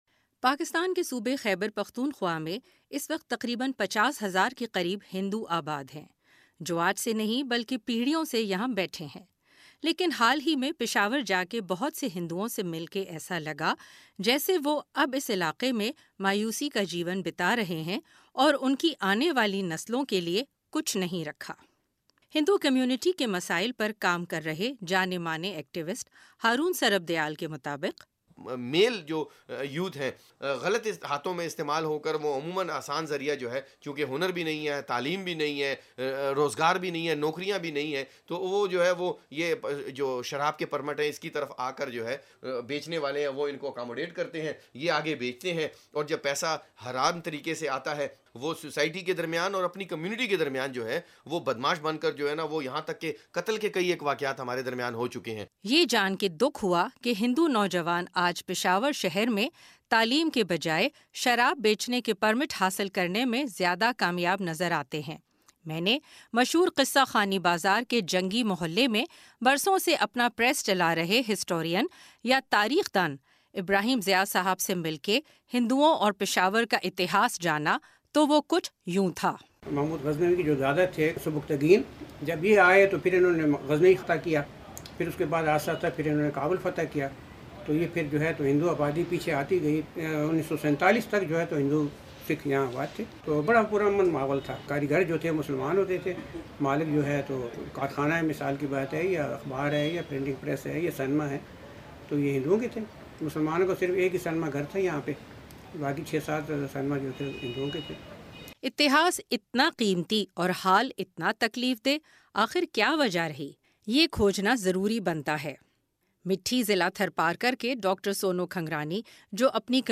ख़ास रिपोर्ट